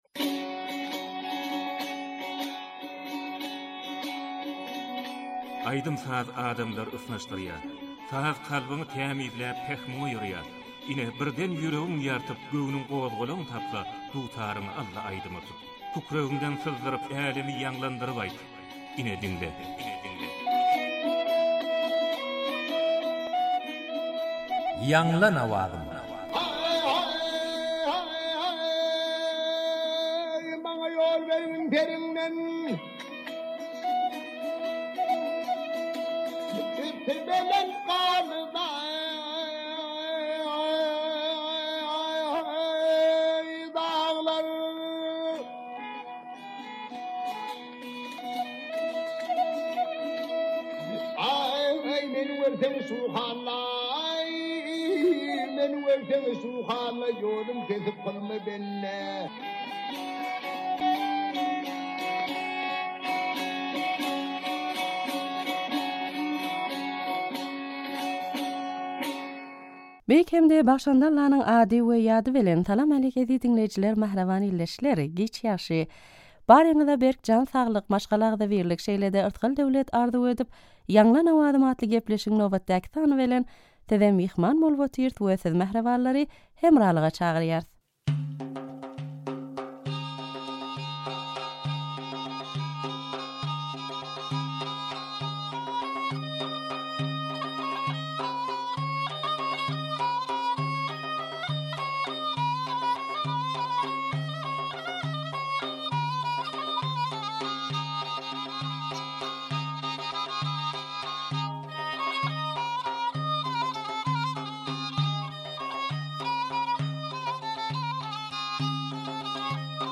turkmen owaz aýdym